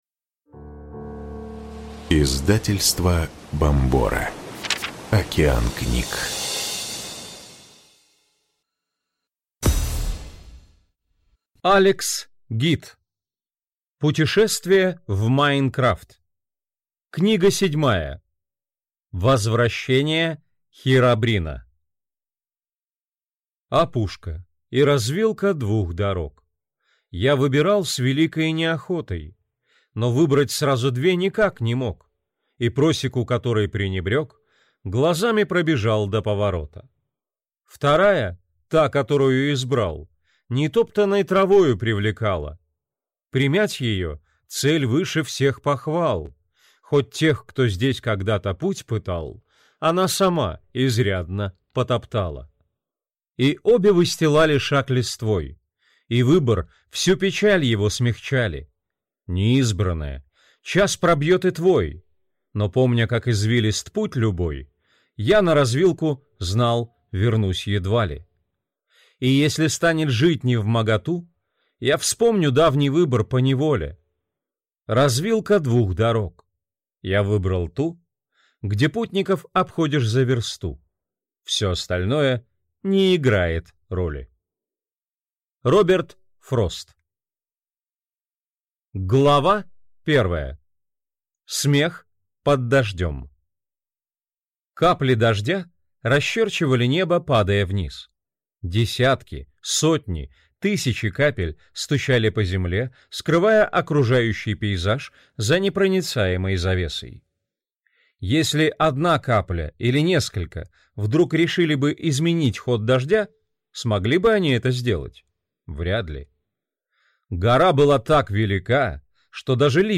Аудиокнига Возвращение Хиробрина | Библиотека аудиокниг